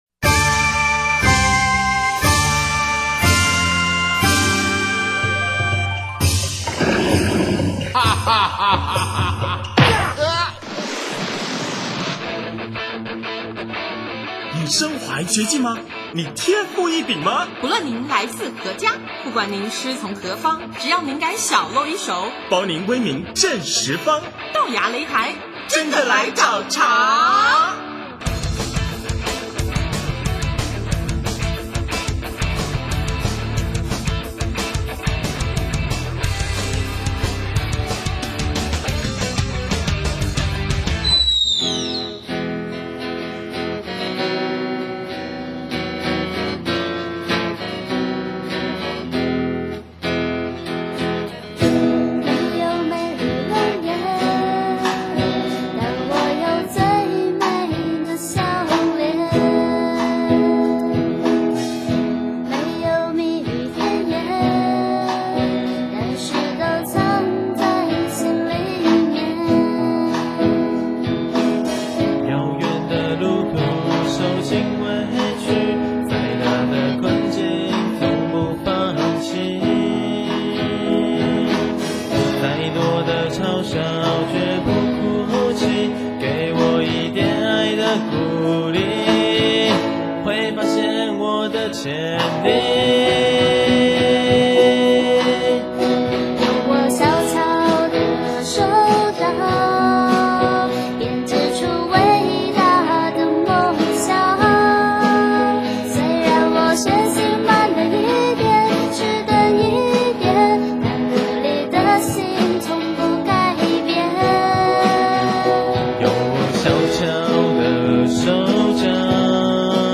【豆芽擂台】54|专访怀仁乐团(一)：青春鼓手